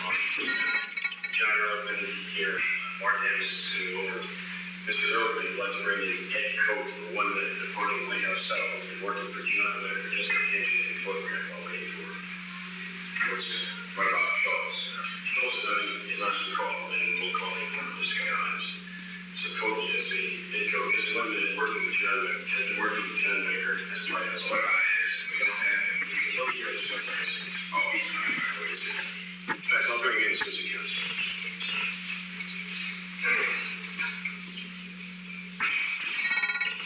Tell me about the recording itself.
Secret White House Tapes Location: Oval Office